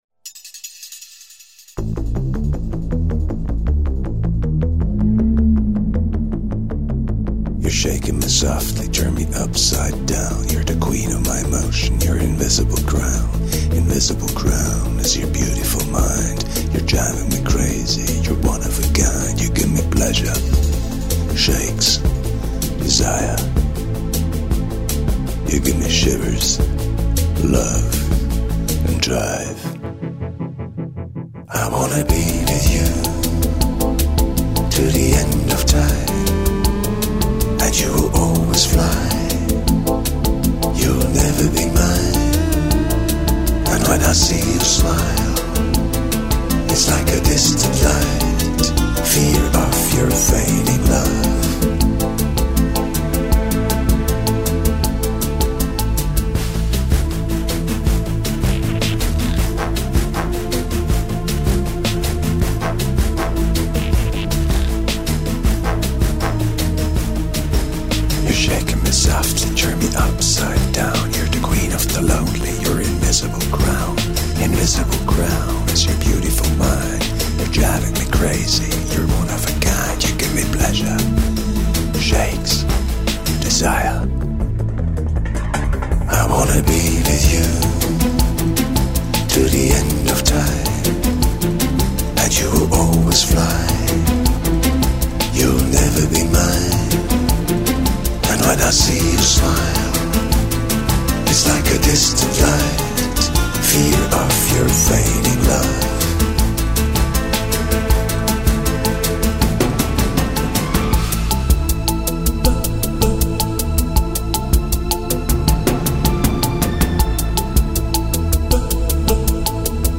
Electronica / pop.
trumpeter